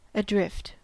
Uttal
Uttal US Okänd accent: IPA : /əˈdrɪft/ Ordet hittades på dessa språk: engelska Ingen översättning hittades i den valda målspråket.